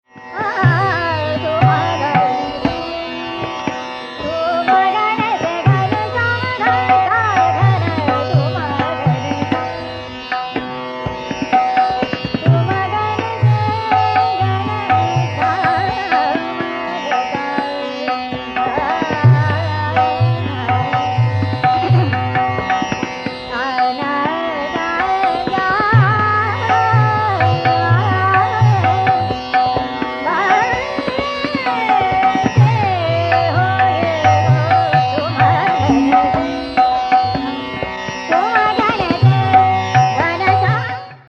S-R-gG-m-P-dD-nN-S
—Kishori Amonkar (1999)—
[bandish, e.g. 9:49] n(nPnP)mP m\g gmRS R m, mPDn mPDNS (N)S n(m)P m\R, Rm RSR m…
AUD-Meerabai-Malhar-Kishori-Amonkar.mp3